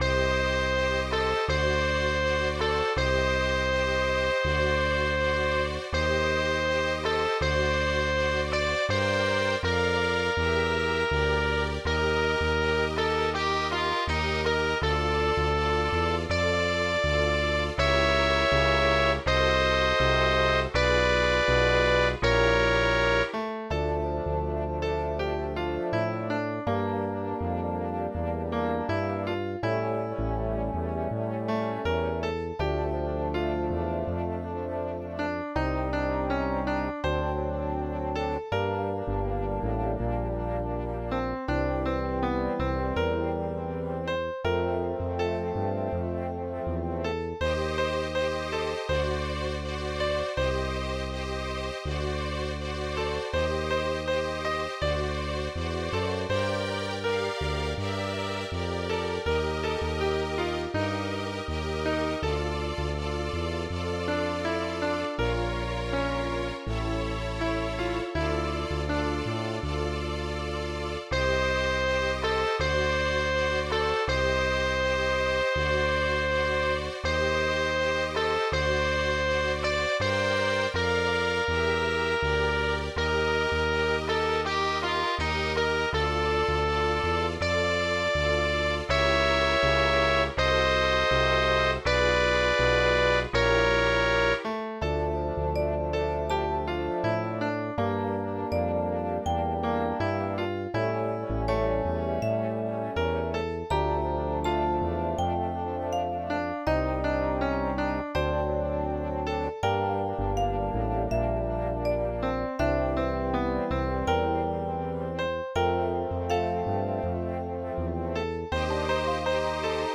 Слова б"ють в самісіньке серце, а мелодія повторює ці удари...